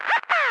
radio_random5.ogg